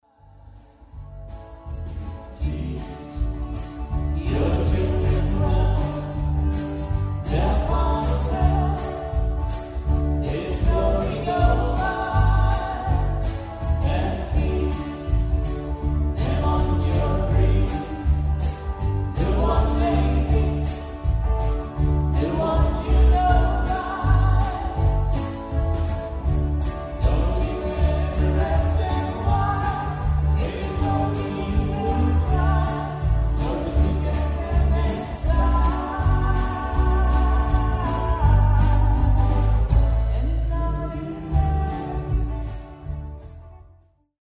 Eén repetitie was in een garaga… dat gaf nogal wat galm….
Stukje met galm: